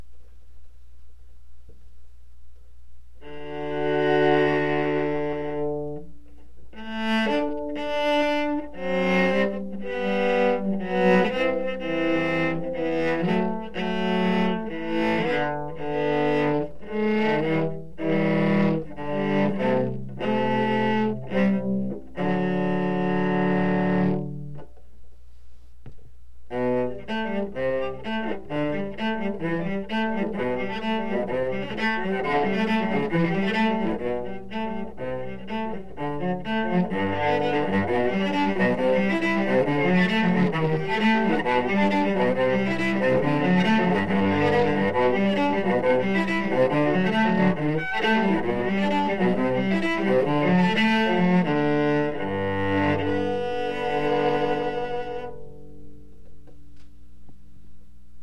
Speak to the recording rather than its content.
Please note that i do not have good recording software, and that these are all first trys on songs.